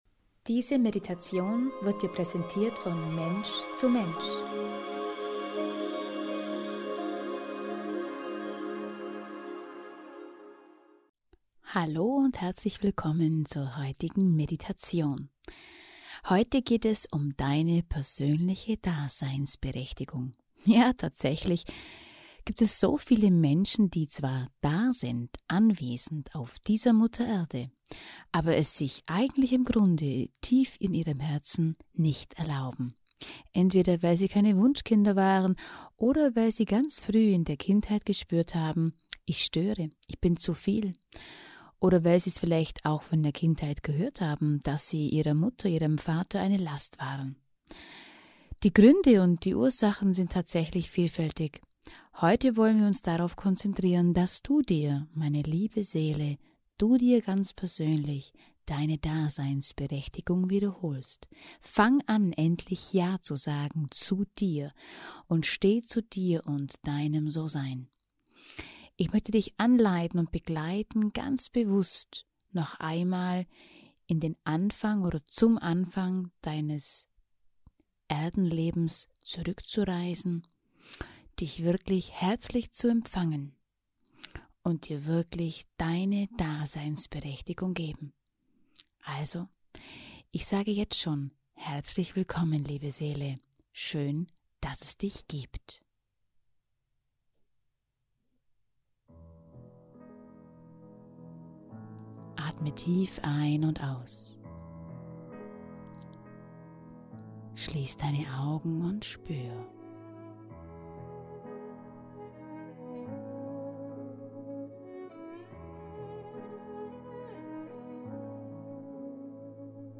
In dieser Meditation geht es darum, dass du wirklich Ja zu dir auf dieser Mutter Erde sagst.